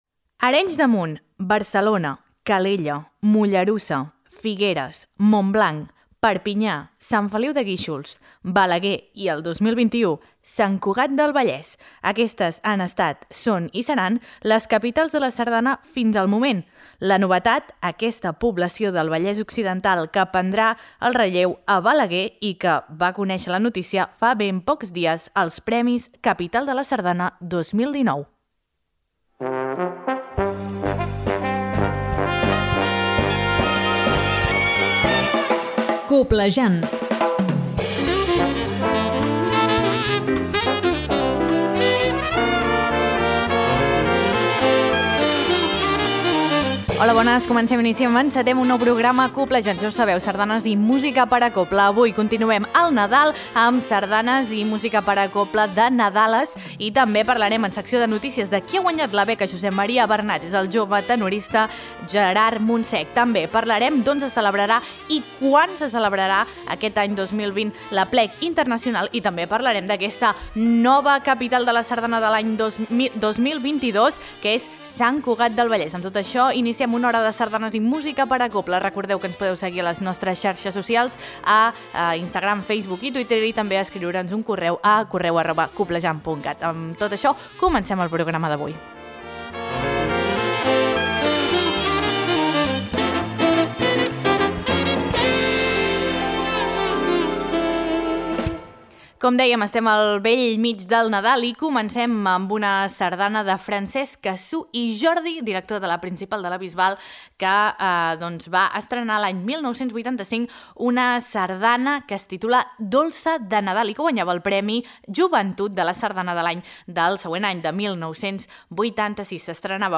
A les Recomanacions Sardanistes també parlem de quins dies se celebrarà l’Aplec Internacional d’aquest proper 2020 i amenitzem el programa amb temàtica nadalenca escoltant sardanes i també música de cobla d’arranjaments de nadales del nostre país.